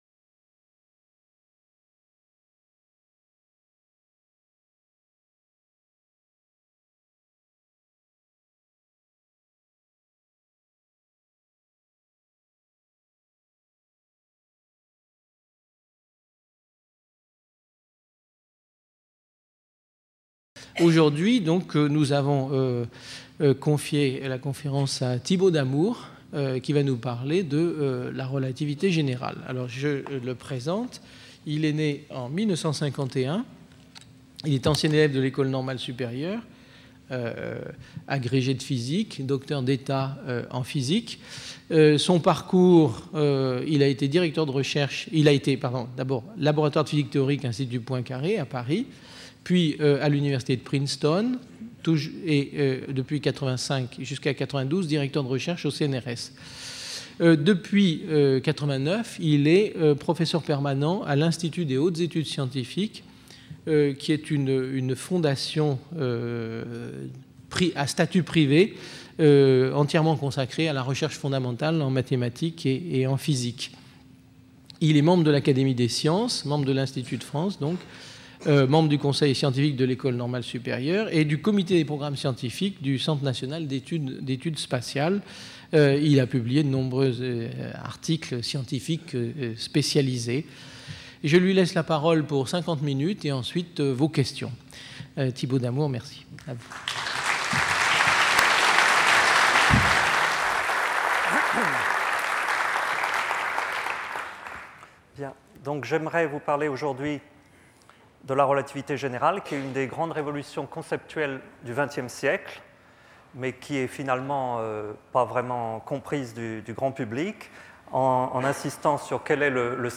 Conférence du 1er juillet 2000 par Thibault Damour.